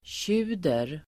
Ladda ner uttalet
tjuder.mp3